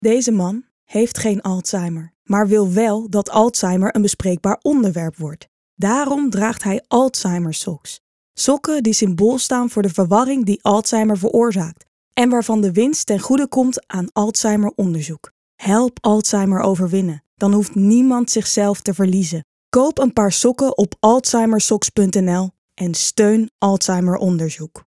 Voice Demo